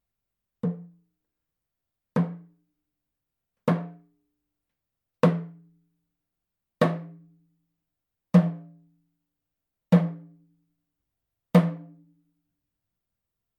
ネイティブ アメリカン（インディアン）ドラム NATIVE AMERICAN (INDIAN) DRUM 10インチ（deer 鹿）
ネイティブアメリカン インディアン ドラムの音を聴く
乾いた張り気味の音です